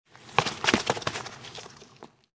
Звуки мяча в футболе
Мяч катиться по траве